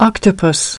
3.Octopus /ˈɑːk.tə.pəs/ : con bạch tuộc